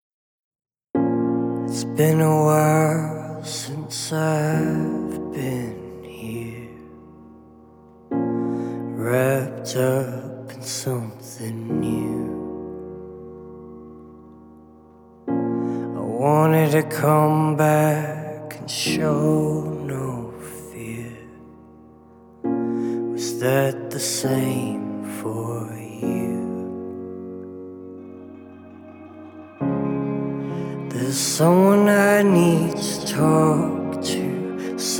Жанр: Поп / Рок / Инди / Альтернатива